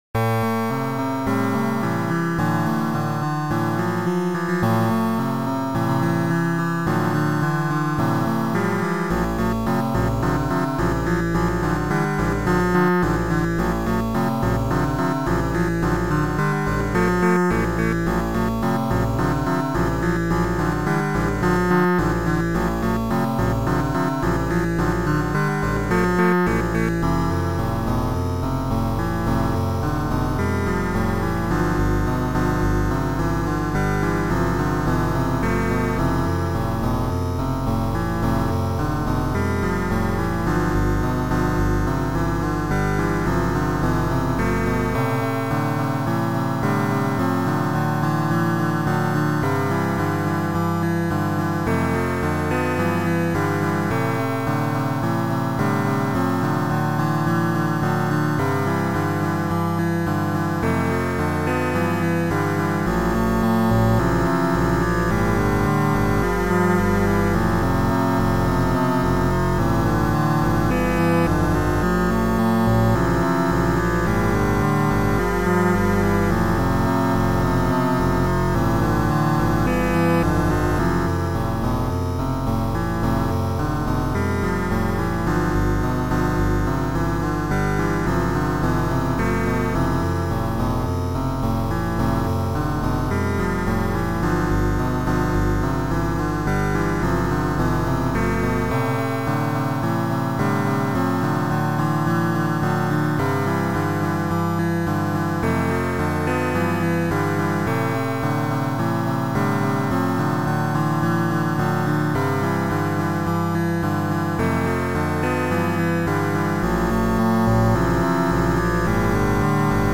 Music ( Noisetracker/Protracker )